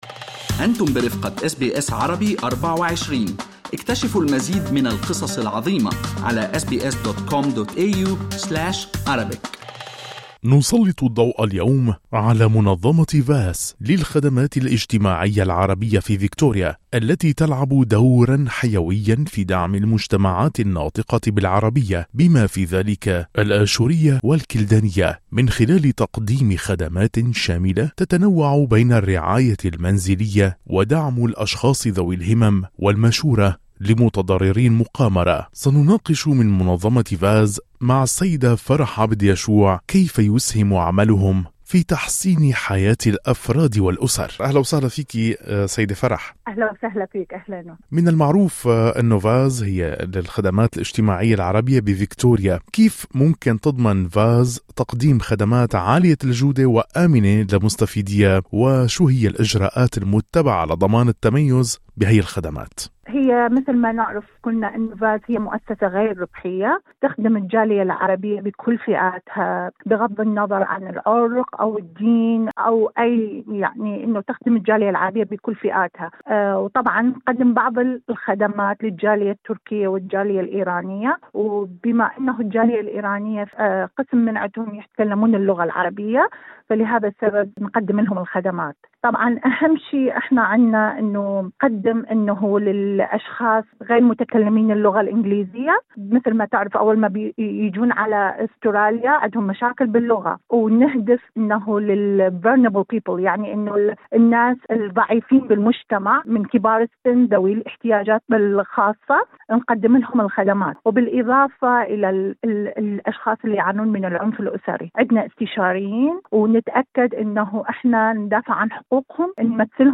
في إطار السعي المتواصل لدعم المجتمعات الناطقة بالعربية في فكتوريا، تبرز منظمة VASS للخدمات الاجتماعية العربية كإحدى الركائز الأساسية التي تقدم يد العون والمساندة لأفراد هذه المجتمعات، بما في ذلك الأشورية والكلدانية، من خلال توفير مجموعة واسعة من الخدمات الاجتماعية. في لقاء حصري